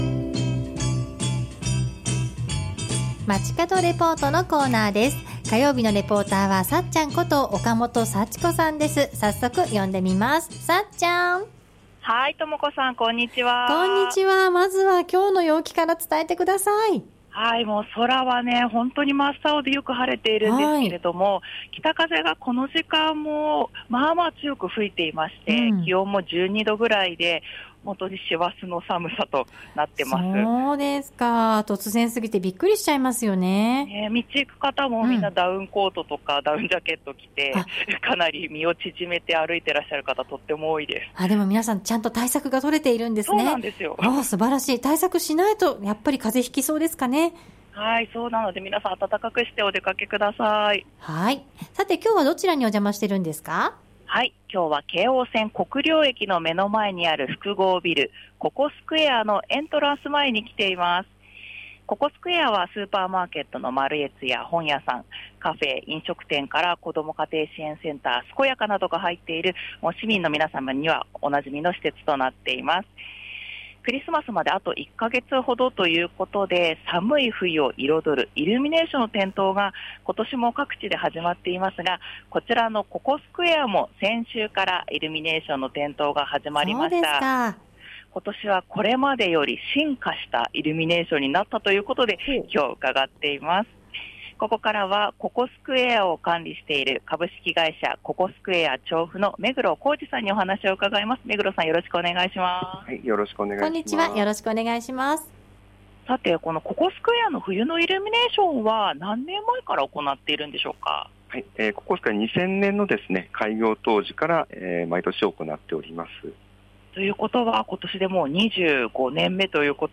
中継は京王線・国領駅の目の前にある複合ビル「ココスクエア」のエントランス前からお届けしました。